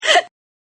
Woman Gasp Sound Effect Free Download
Woman Gasp